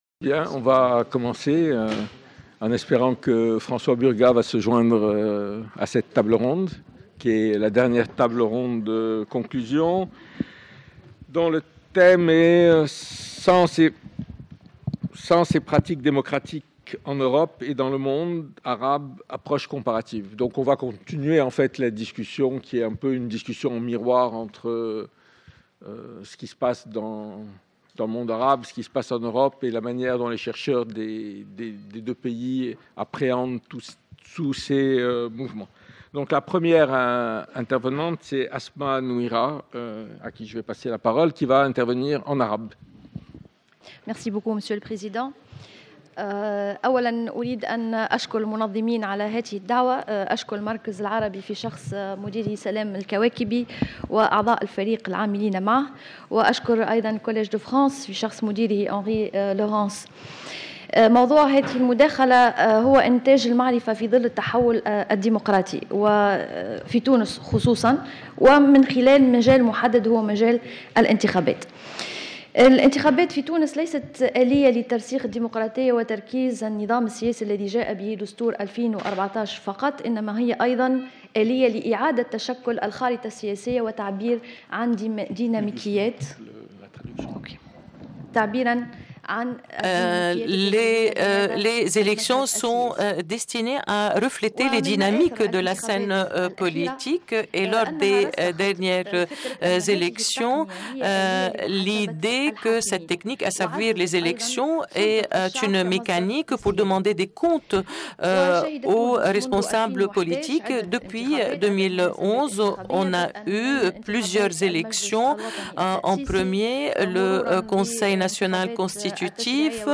Cette quatrième table ronde analyse les impacts des printemps arabes en Europe, notamment pour ce qui est de la production et la diffusion des savoirs sur les sociétés arabes entre les deux rives de la méditerranée.